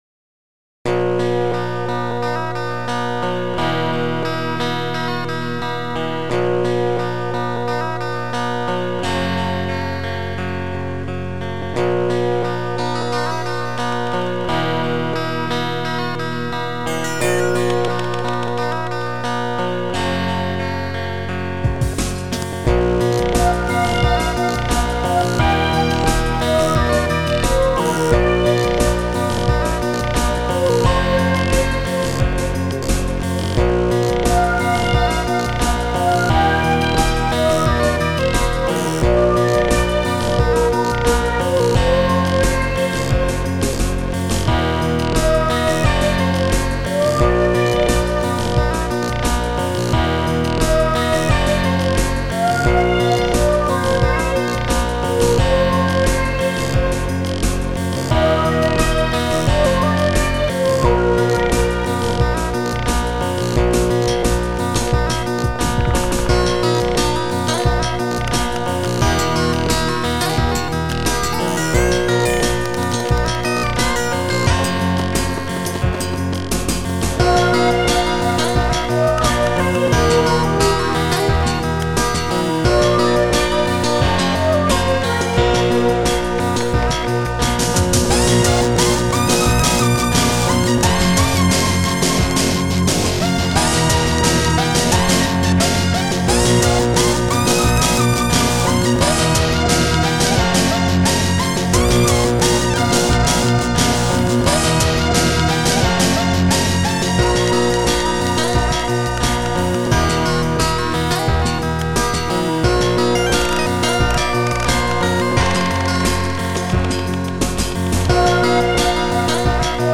Protracker Module  |  1996-09-11  |  455KB  |  2 channels  |  44,100 sample rate  |  2 minutes, 59 seconds
Protracker and family
- gitarowe -